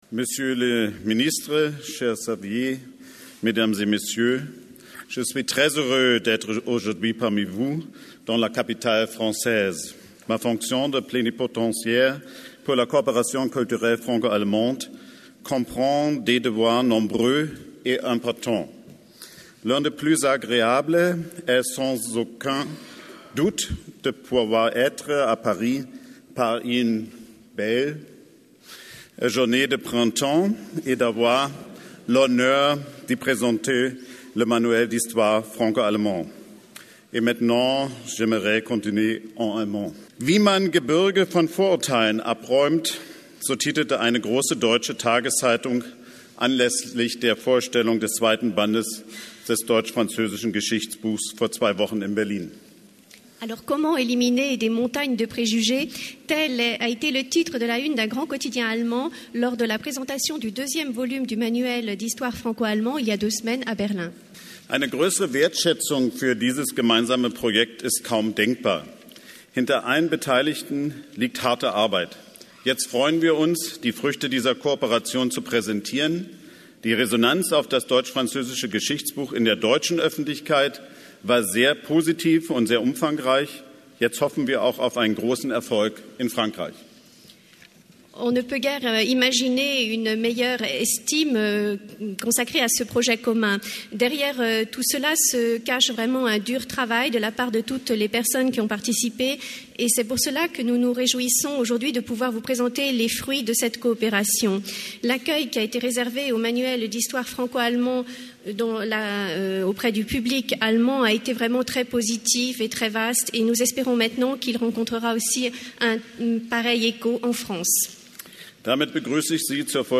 Die Ansprache von Xavier Darcos:
Bei einer Feierstunde im Großen Salon der Sorbonne haben am 23. April 2008 der französische Bildungsminister Xavier Darcos und der Bevollmächtigte für die deutsch-französische kulturelle Zusammenarbeit, der Regierende Bürgermeister von Berlin, Klaus Wowereit, den zweiten Band des deutsch-französischen Geschichtsbuches vorgestellt.